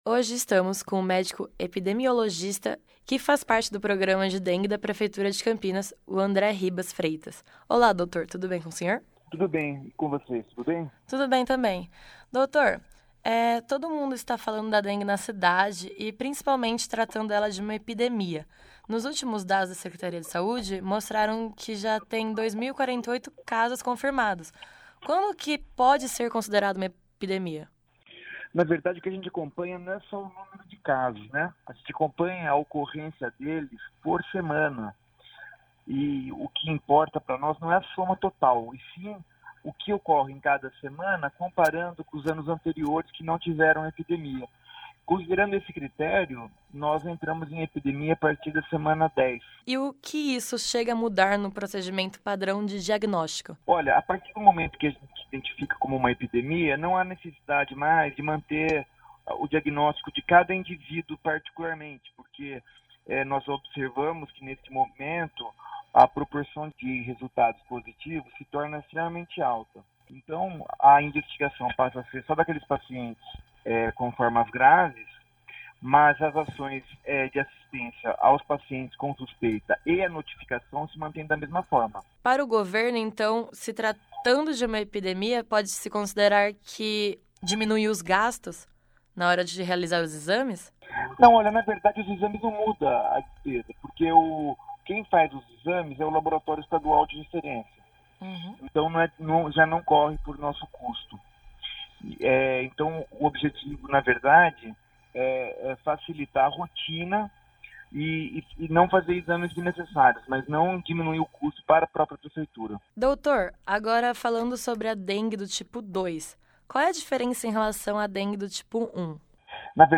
Destaque Entrevistas